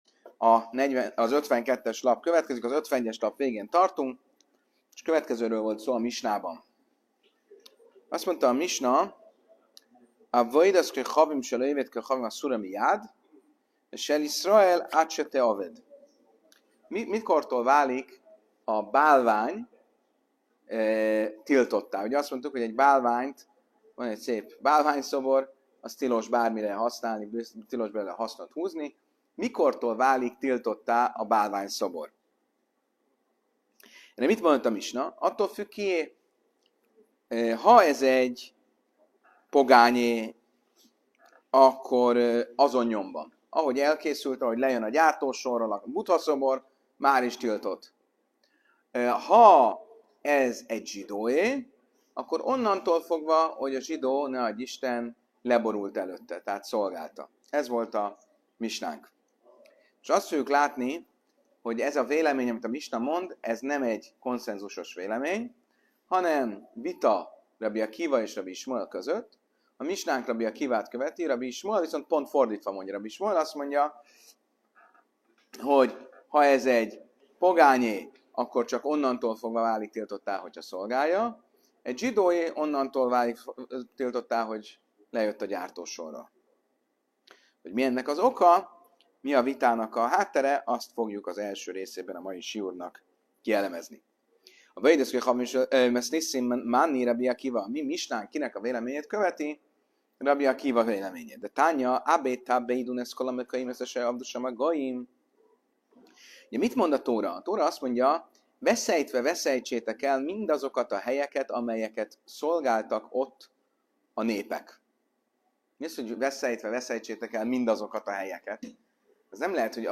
A mai előadás központi kérdése az volt, hogy mikortól válik egy bálvány (vagy a hozzá kapcsolódó eszköz) tiltottá: az elkészültétől, vagy csak attól kezdve, hogy ténylegesen imádták? Az Ávodá Zárá 52-es lapja ezen a vitán keresztül mélyre ás a bálványimádás definíciójában, és számos történelmi és halachikus példát is vizsgál, köztük egyiptomi „alternatív szentélyt”, a frigyláda másolatát és még az aranyérmék eredetét is.